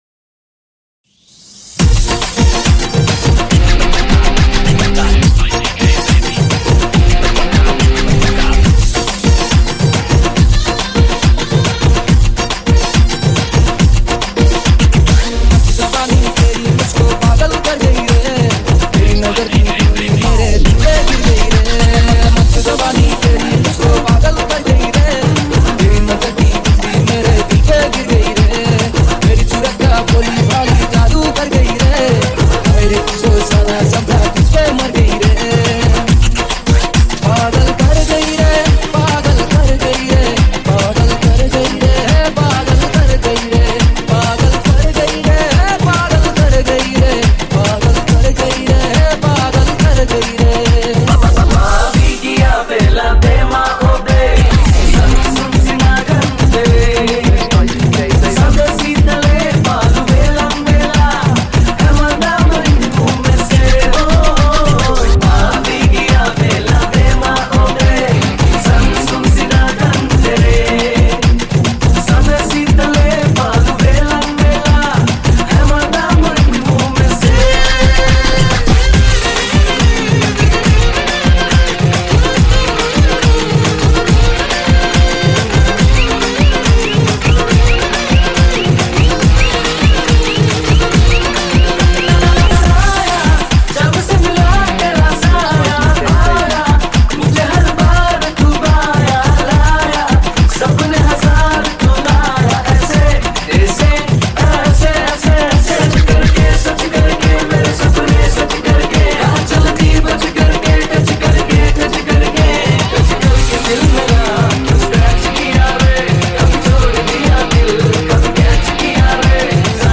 High quality Sri Lankan remix MP3 (3.8).
remix